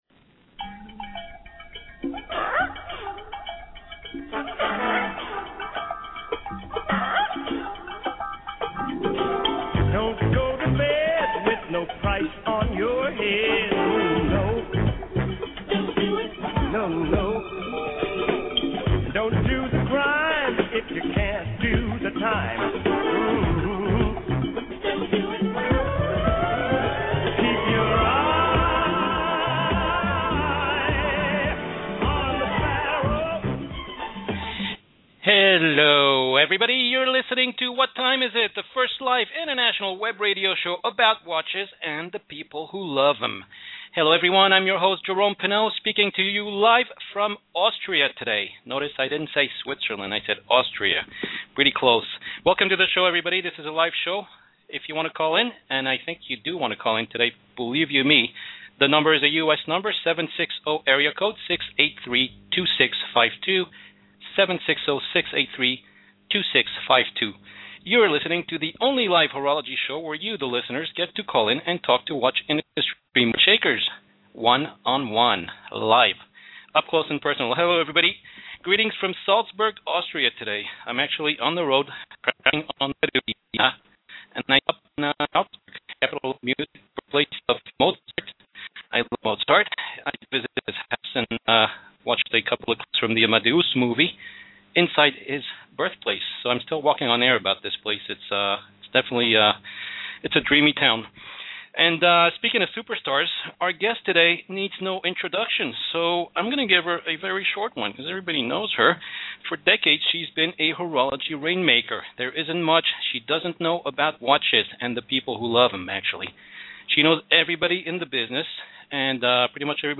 “What Time Is It?” is the first live international web radio show about watches and the people who love them!